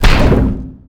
energy_blast_small_04.wav